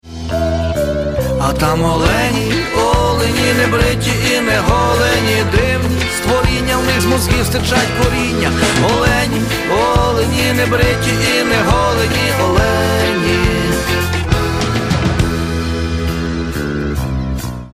• Качество: 128, Stereo
фолк-рок